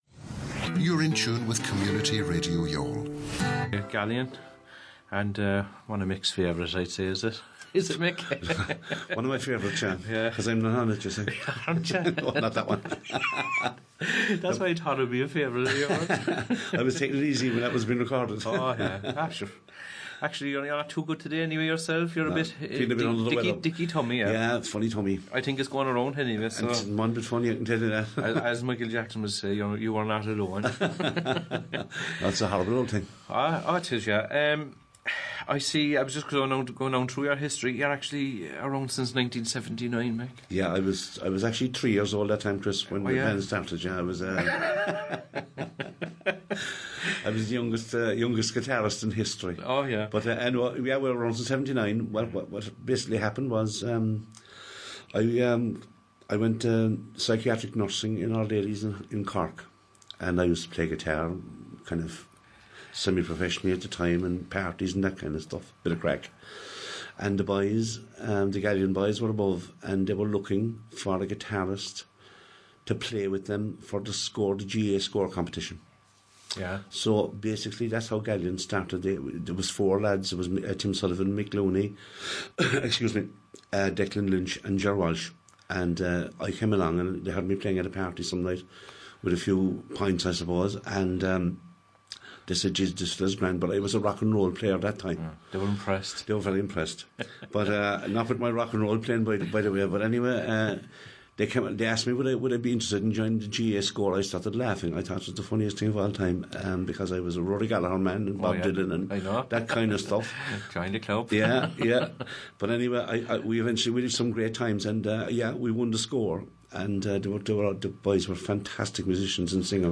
This interview comes from 'Youghal Ready For This' which airs Monday to Friday 12 - 2 on CRY104FM.